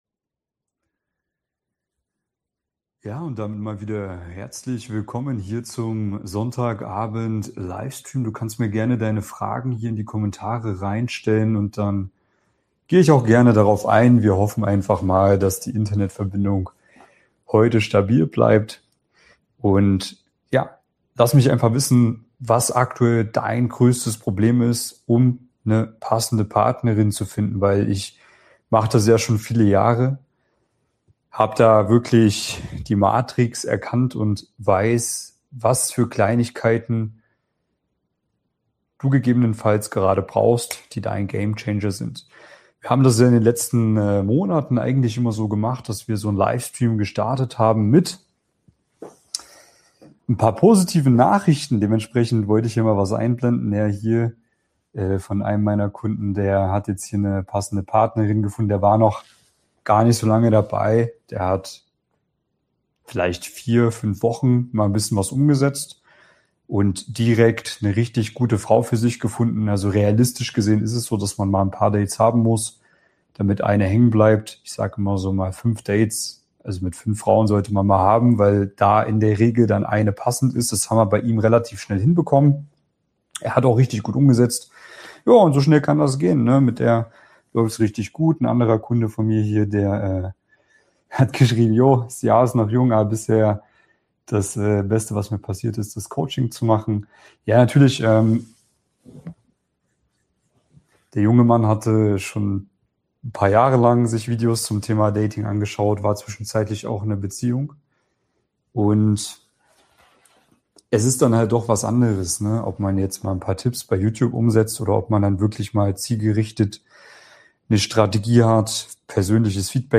In diesem Q&A Livestream geht es um Dating, Mann Frau Dynamiken, Anziehung, Partnersuche und die Frage, warum so viele intelligente, erfolgreiche Männer t...